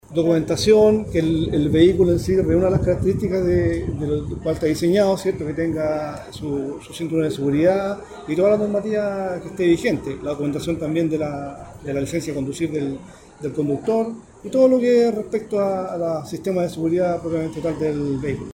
Cuna-Jefe-Zona-Biobio-de-Carabineros-general-Renzo-Miccono-transporte-escolar.mp3